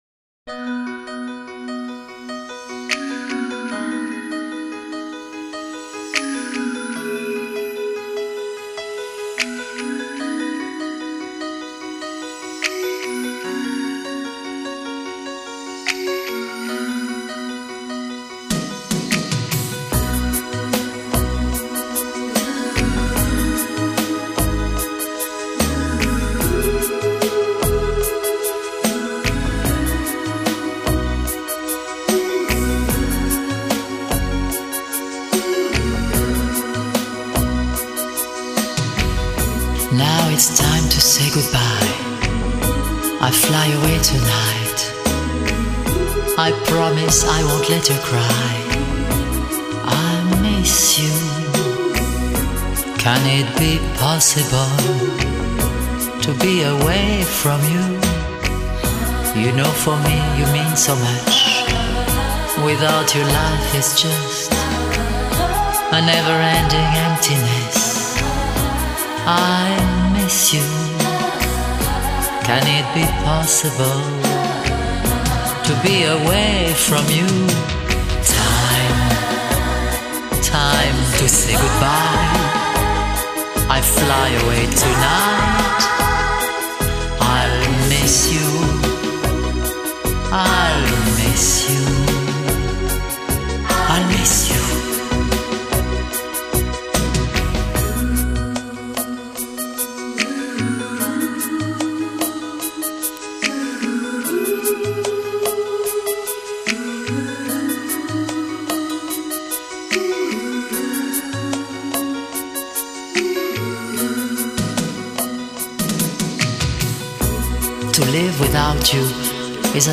Genre: Pop
女声轻轻地吟唱，思念如风，绵绵不绝……